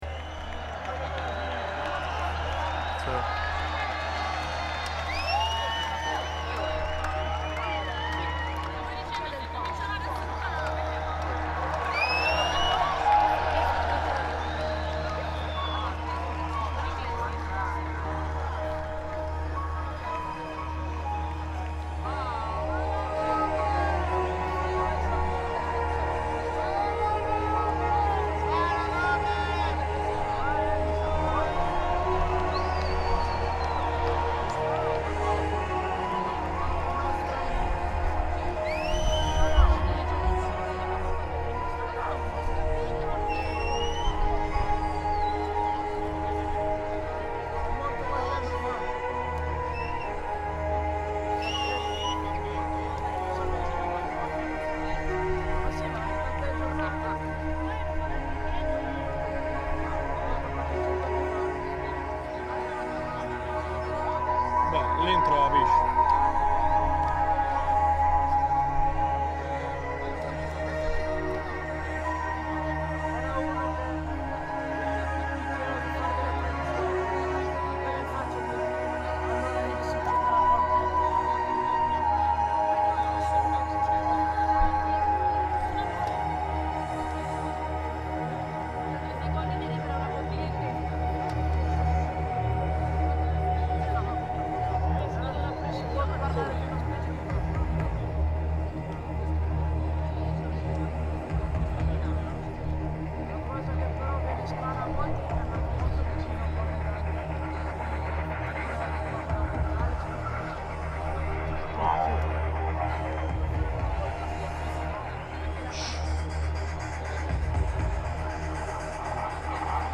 quality is very good.